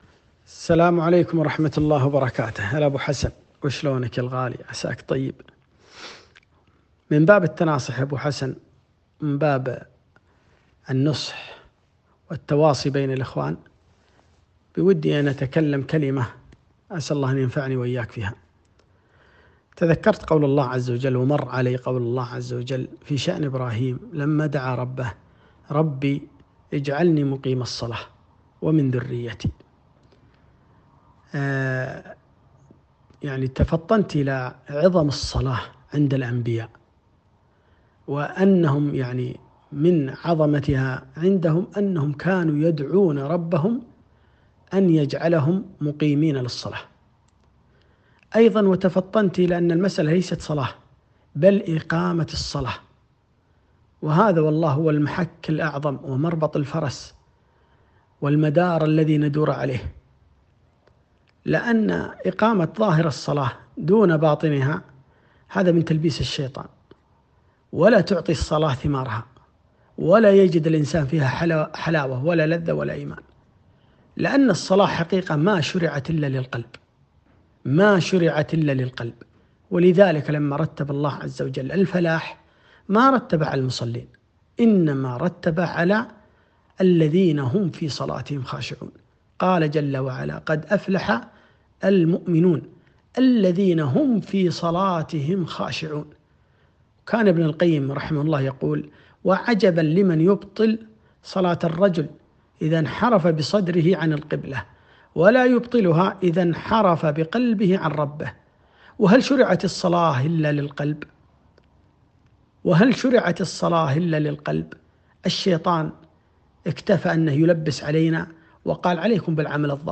رب اجعلني مقيم الصلاة - كلمة